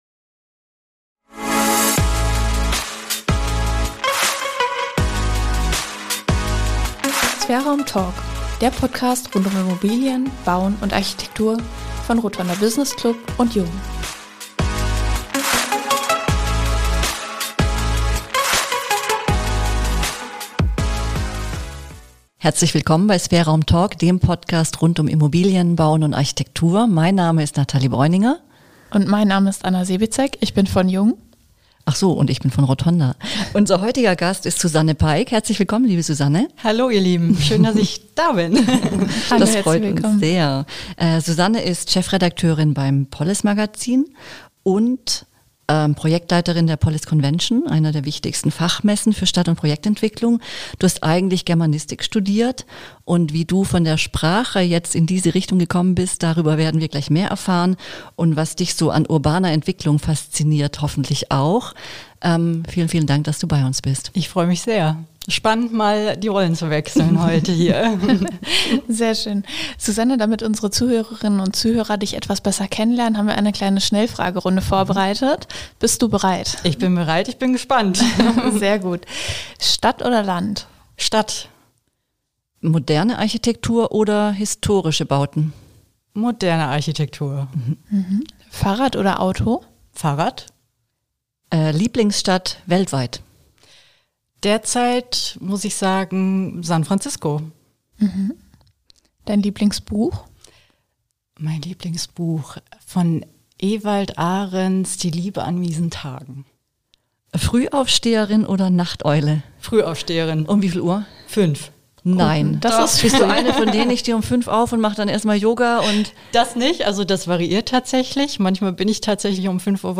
Warum sie die polis Convention als Klassentreffen der Stadtentwicklung bezeichnet, wie wir wieder mehr Mut zur Vision entwickeln – und was sie bei PR-Arbeit häufig vermisst, erzählt sie im Gespräch.